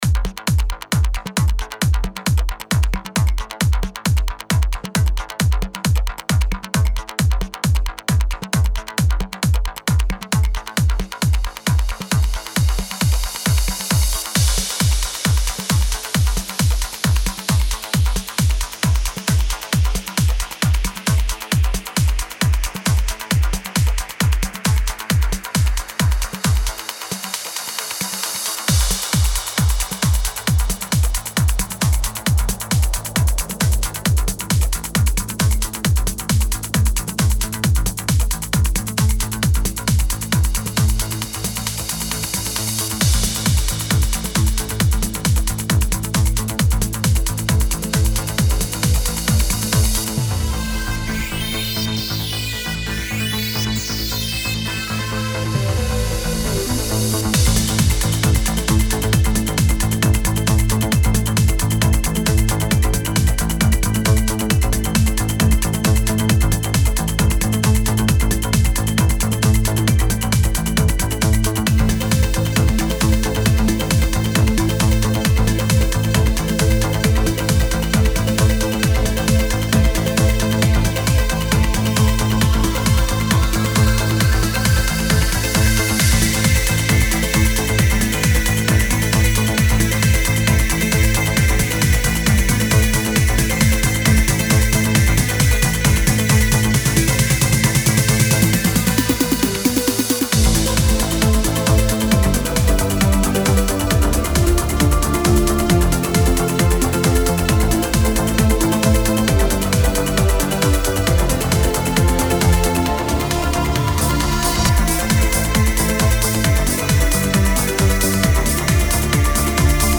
Genre: Progressive.